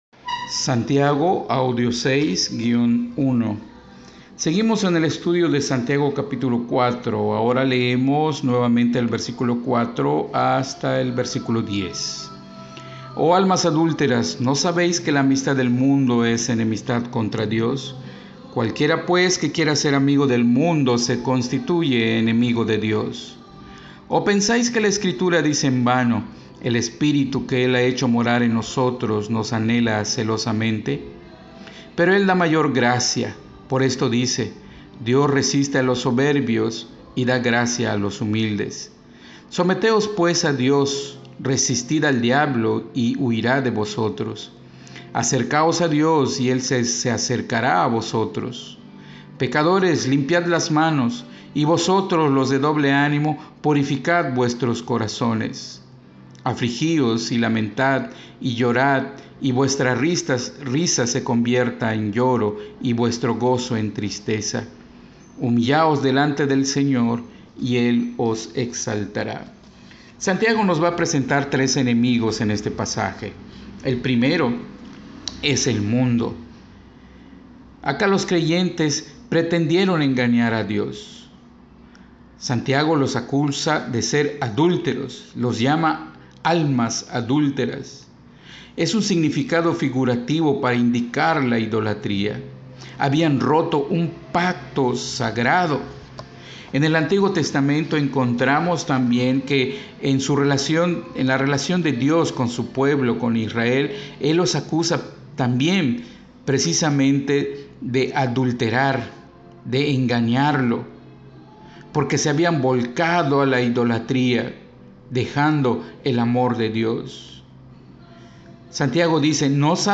Llegamos a la sexta clase del Estudio de la Epístola de Santiago, que nos proporciona la Escuela Bíblica Ibew